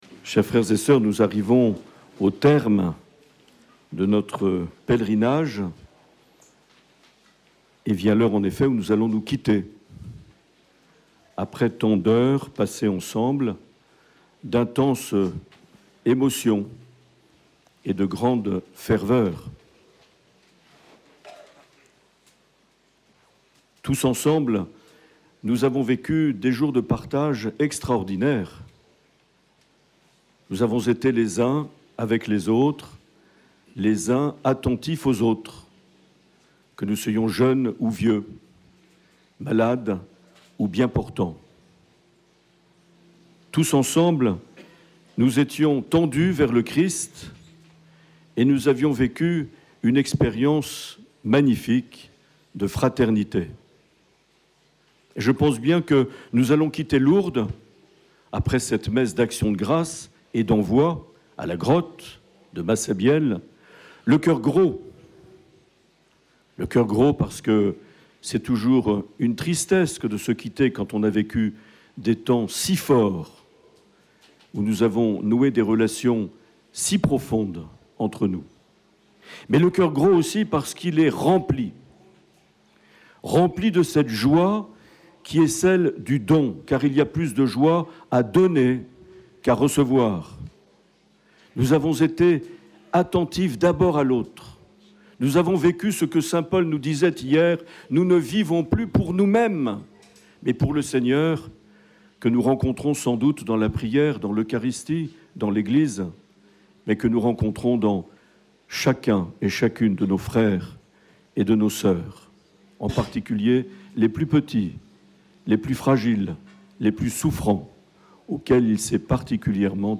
18 septembre 2023 - Lourdes - Messe avec l’HBB à la grotte de Massabielle
Une émission présentée par Monseigneur Marc Aillet